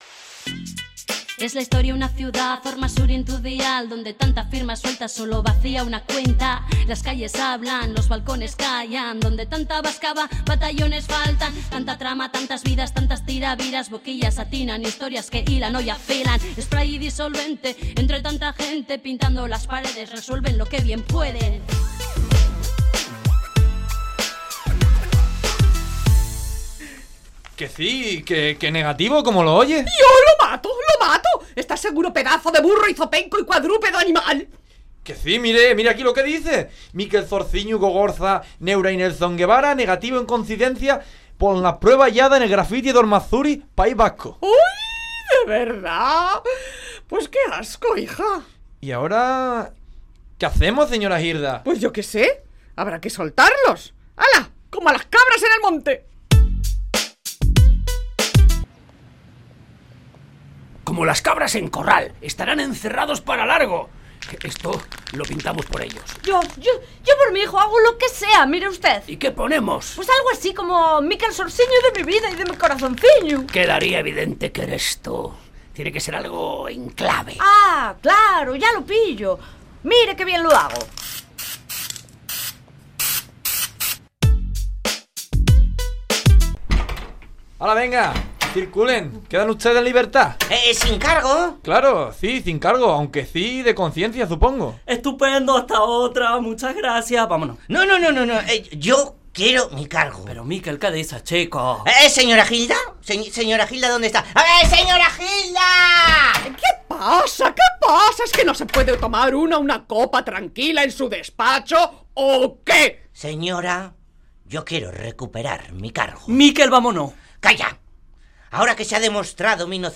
radio-ficción